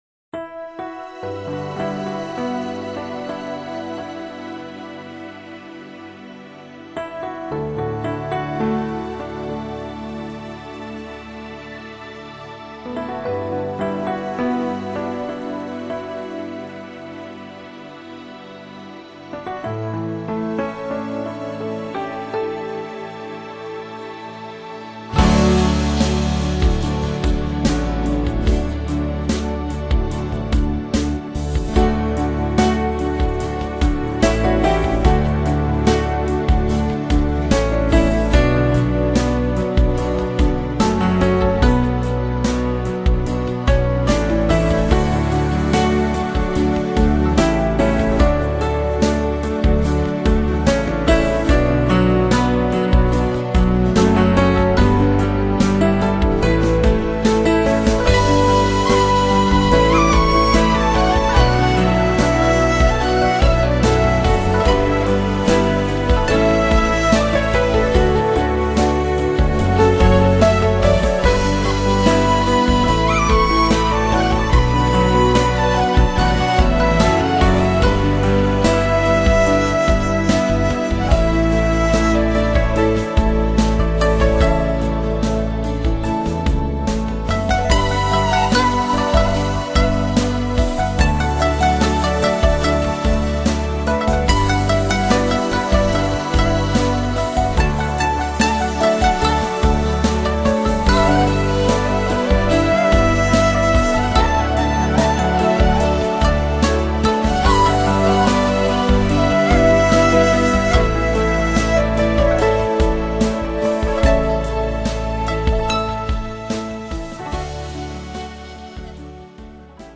欧美纯音
音乐飘逸、洒脱，有着海风的清新、清爽，海港的宁静、温馨。
这是一首需用心去倾听和感受的曲子，有伤感，有无奈，还有渴望和期盼。
飘逸的曲子，清清淡淡，如行云流水般的音律，洁净而从容，在遥远的梦幻里流连，寻找渴望已久的宁静。